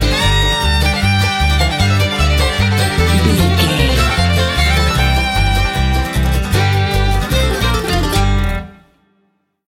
Ionian/Major
banjo
violin
double bass
acoustic guitar